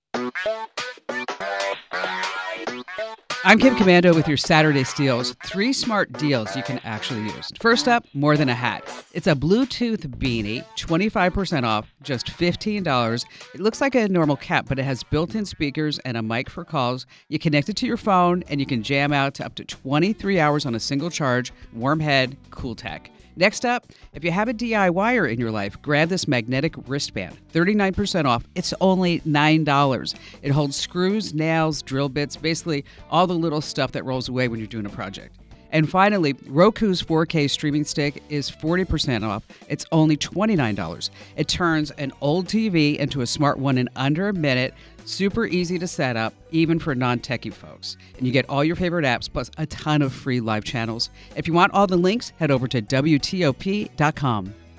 Kim Komando breaks down the best deals of the weekend.